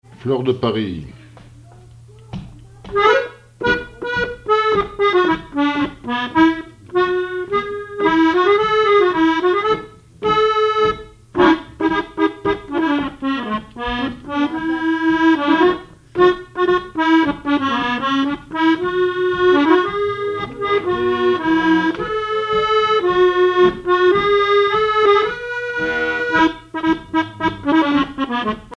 accordéon(s), accordéoniste
danse : marche
Répertoire à l'accordéon chromatique
Pièce musicale inédite